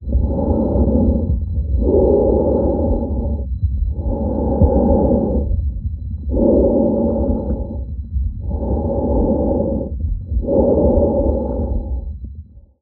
呼吸音のクイズ問題1.mp3